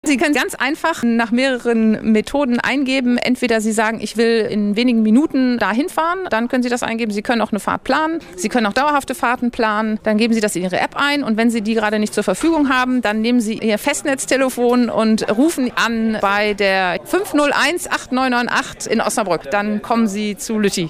Landrätin Anna Kebschull erklärt, wie die Lütti App funktioniert.